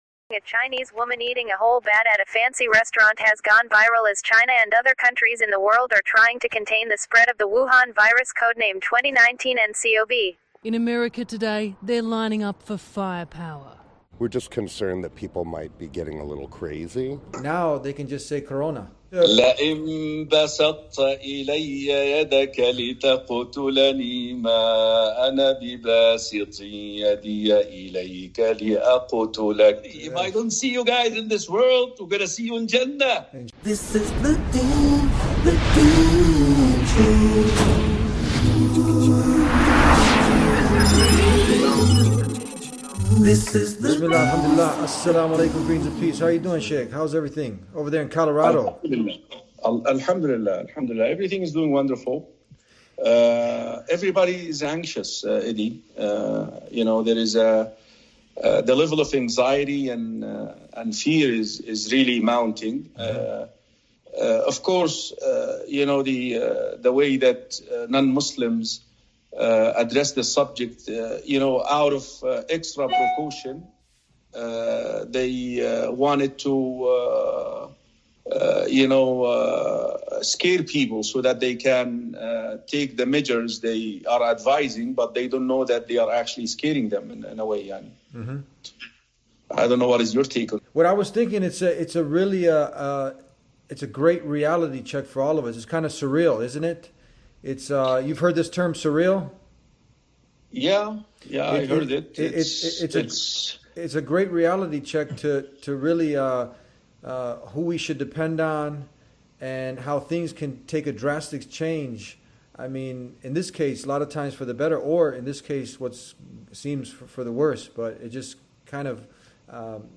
The conversation conveys a message of reassurance and encourages viewers to have faith in Allah’s wisdom, emphasizing the value of relying on one’s Creator during challenging times. Furthermore, the episode brings attention to the Islamic perspective on hardships and suffering, presenting them as opportunities for spiritual growth and reward.